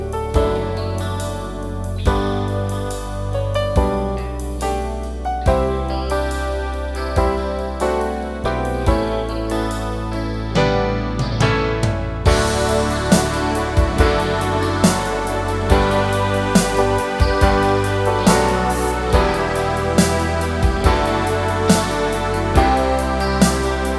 no Backing Vocals Duets 4:13 Buy £1.50